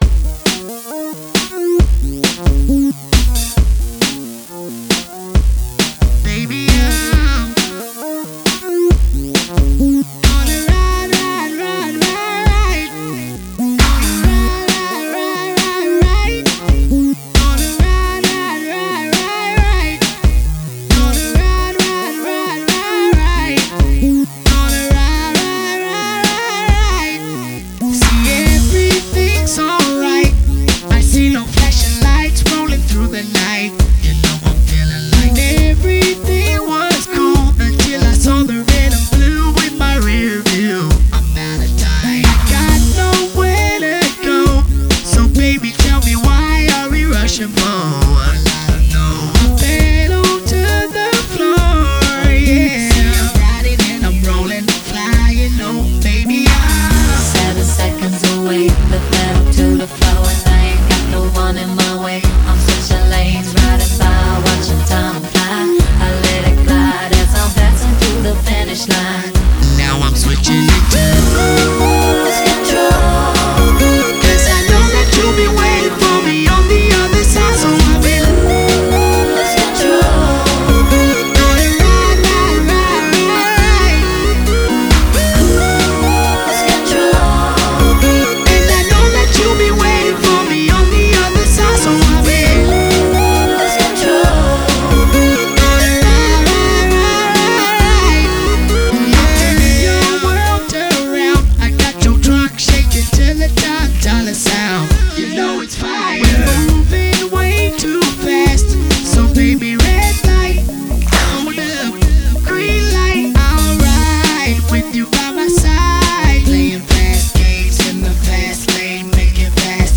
R&B, Hip Hop, Funk, Soul and Rock
talk-box skills that only get better by the song